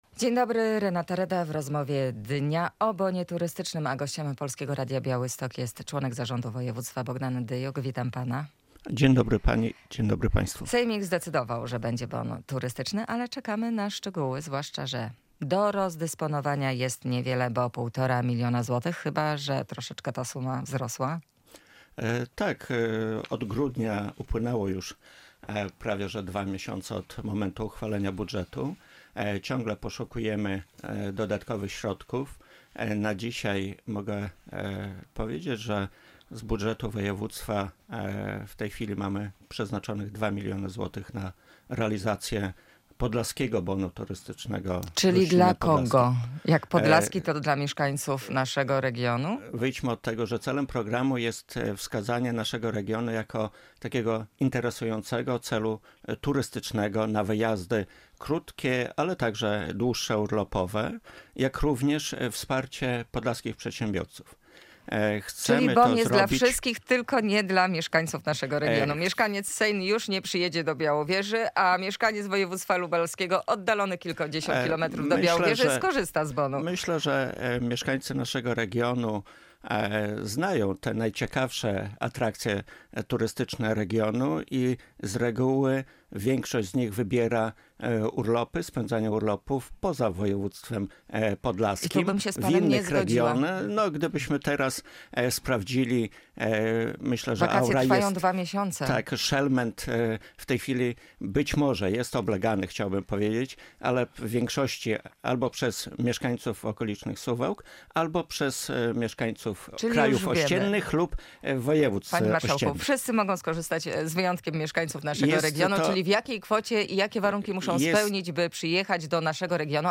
Radio Białystok | Gość | Bogdan Dyjuk - członek Zarządu Województwa Podlaskiego
O tym w Rozmowie Dnia z Bogdanem Dyjukiem, członkiem Zarządu Województwa Podlaskiego.